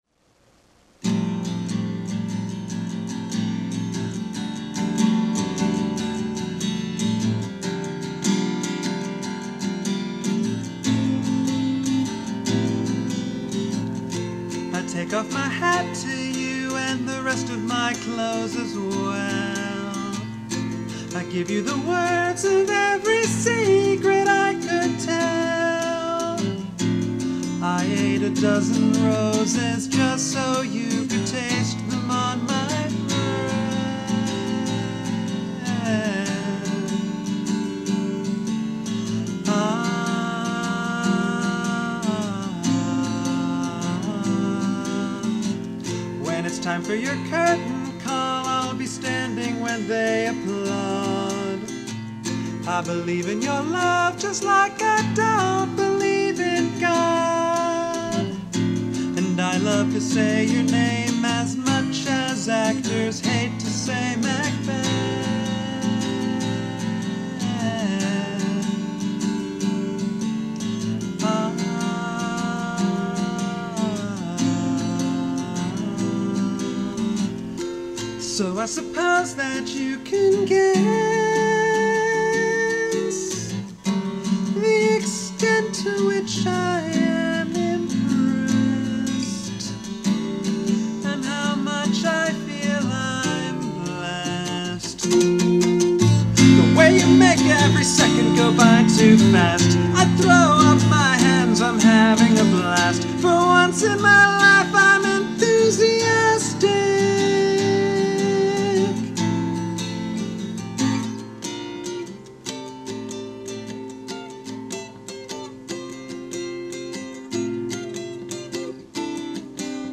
At the time, all I had recorded was the guitar part, but at long last, I recorded a vocal track, so
Then I spliced it all together digitally, burned it to a CD, and sang the song at the ceremony karaoke-style.
As for my thumb, it's been back in shape for a while, so I could have rerecorded the guitar part, but I thought it would be more appropriate to leave it as it is.